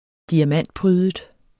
Udtale [ -ˌpʁyːðəd ]